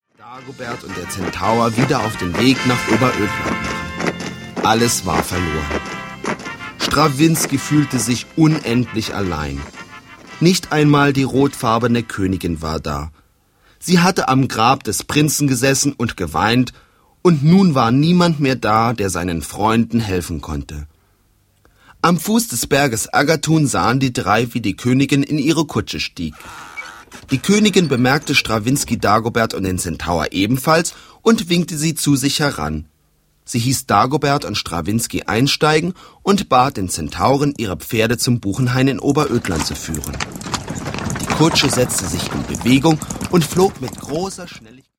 • Sachgebiet: Hörspiele